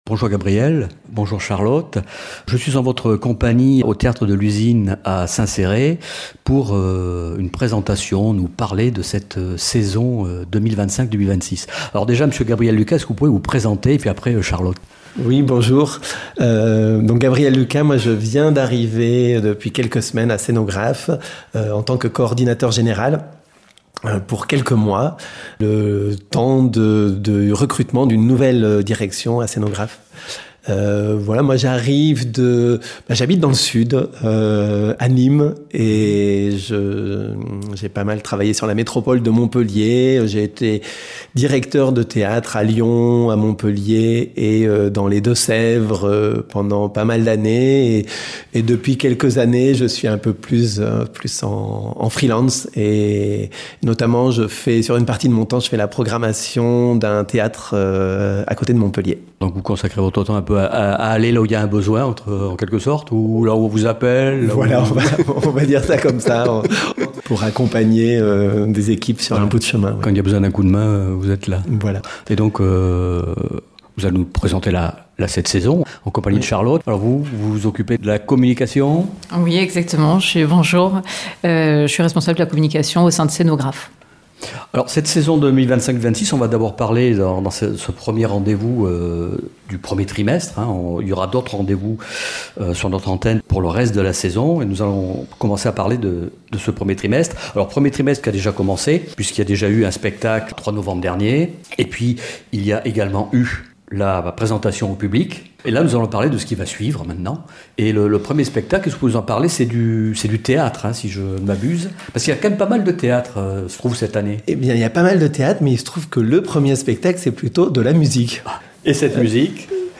Avec ses 2 invités il évoque le début de cette saison